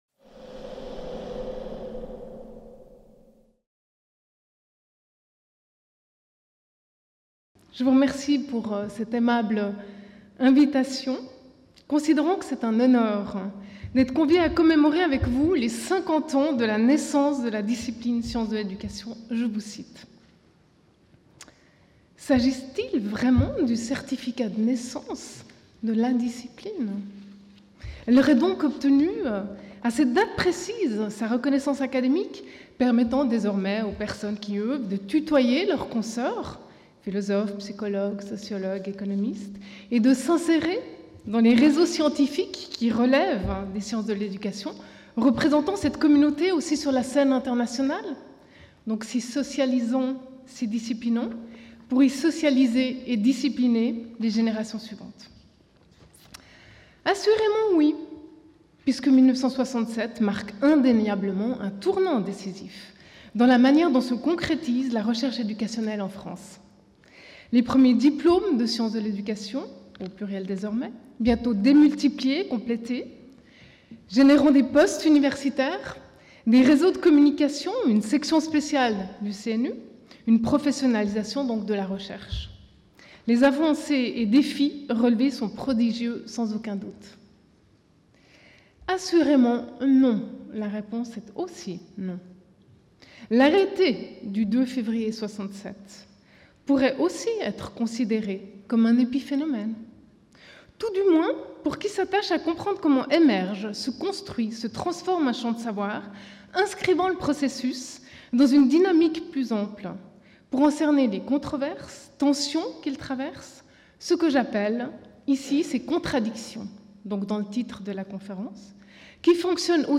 Mises en perspectives historique et internationale des sciences de l'éducation (Amphithéâtre Pierre Daure)